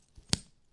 描述：在这里，我试图收集我在家里发现的所有扣件。其中大部分在夹克上，一个手提包上有啷个球，还有一些雪地裤。
Tag: 点击 服饰和配件 扣紧固件